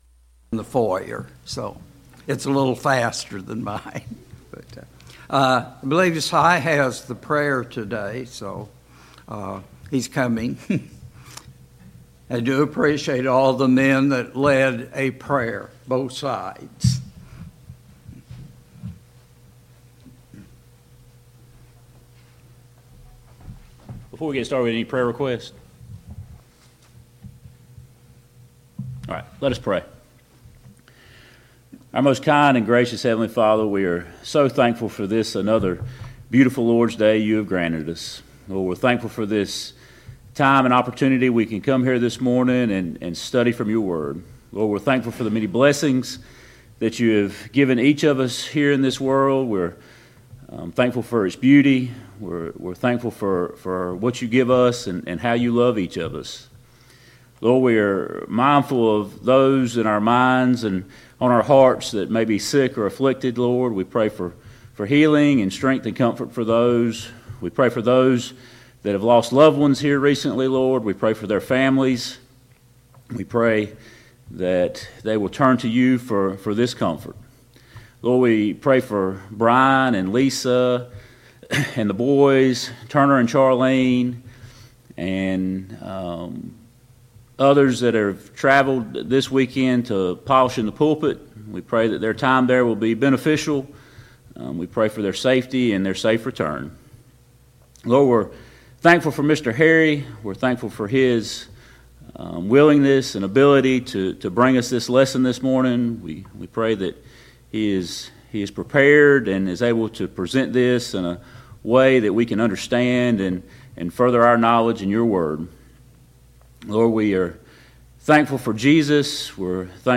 Sunday Morning Bible Class « 20.